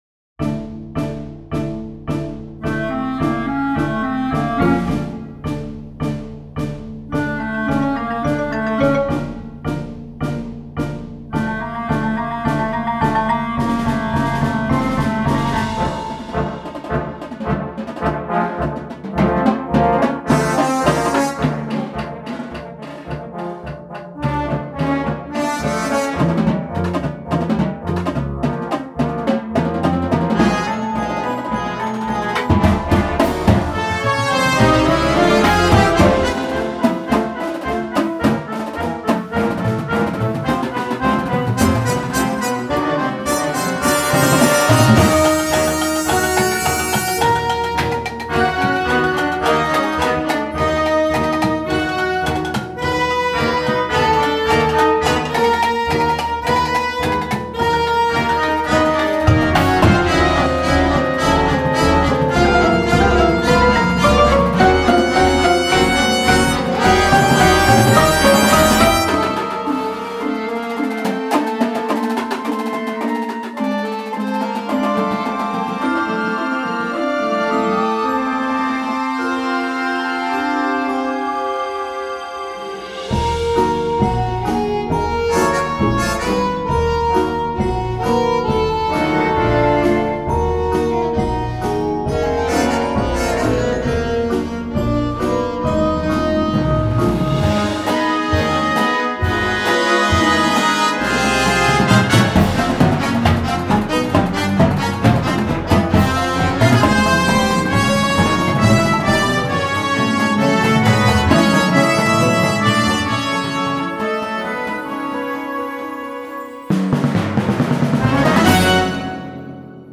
Marching Band Shows
Winds
Percussion